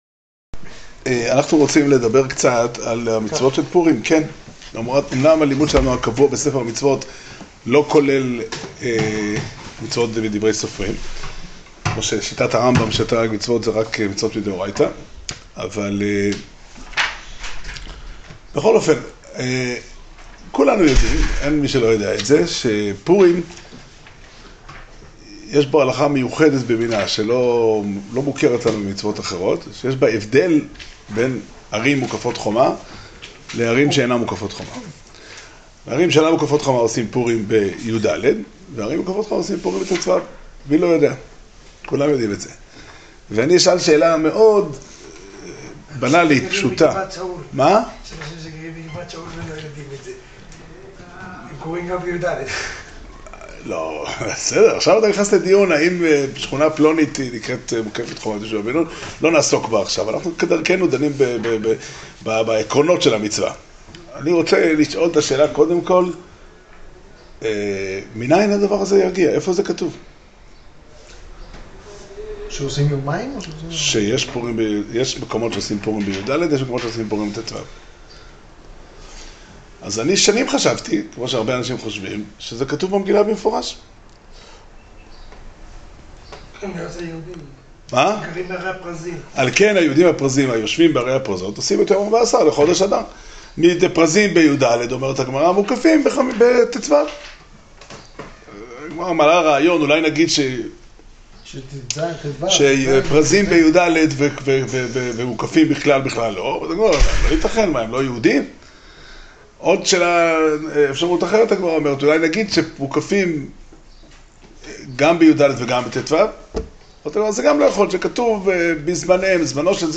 שיעור שנמסר בבית המדרש פתחי עולם